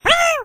雷电精灵Electro Spirit最多可以一下击晕9个目标，语音很有电流感。